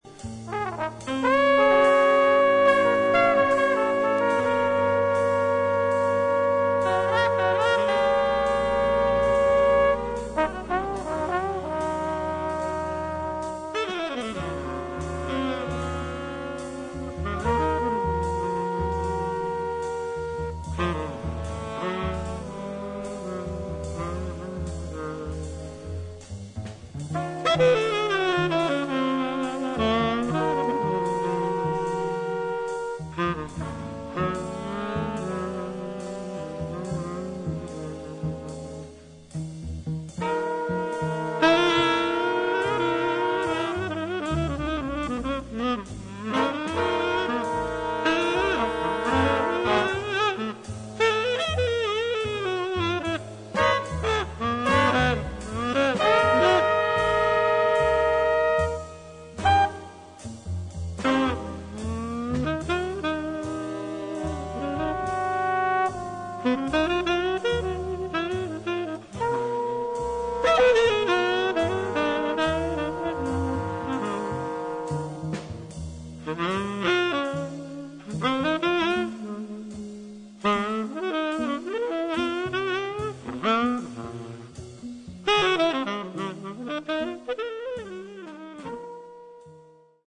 ブルースのモードを基調にしながらも、型に縛られない自由な演奏が魅力の名盤です。